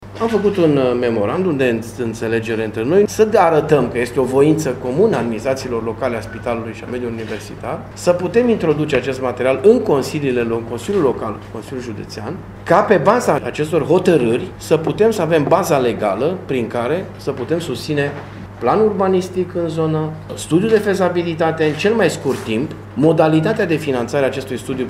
Primarul municipiului Tîrgu-Mureș, Dorin Florea.